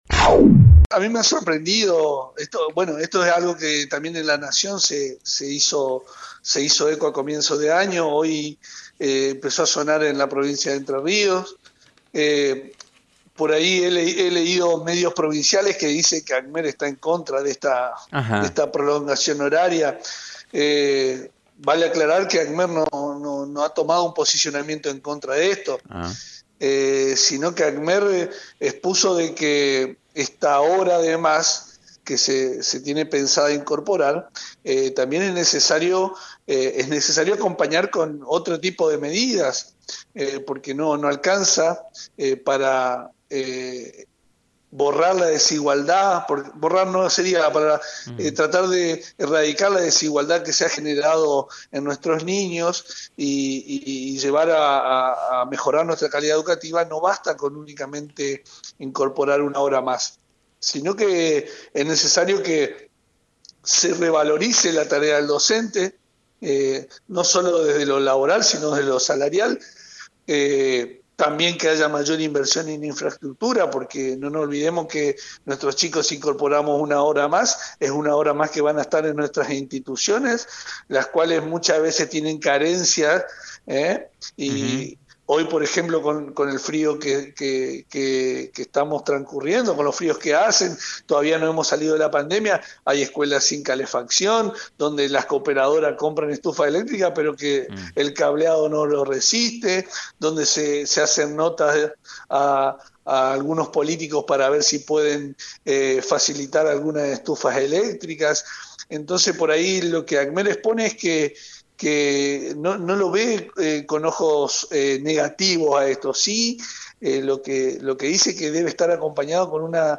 En diálogo con FM 90.3